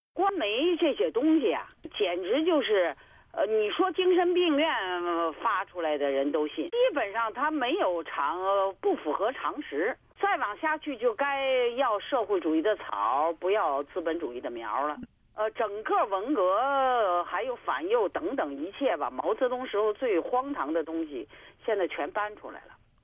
高瑜的电话采访录音